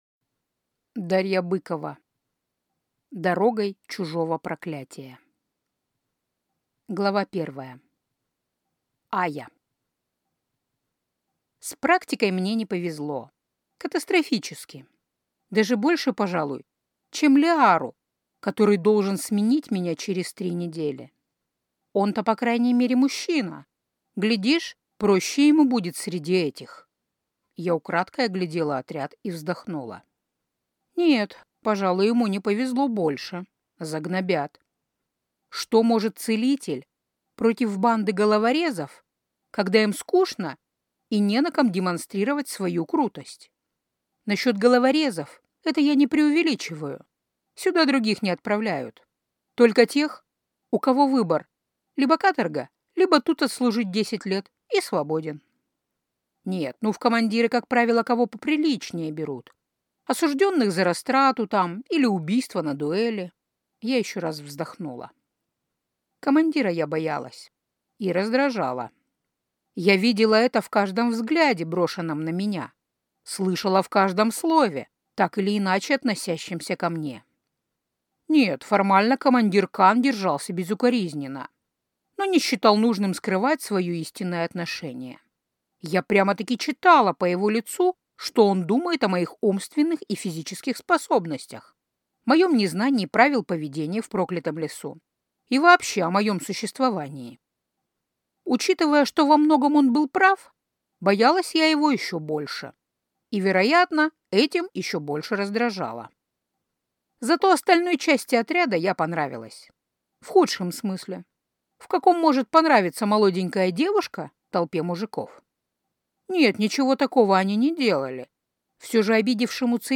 Аудиокнига Дорогой чужого проклятия | Библиотека аудиокниг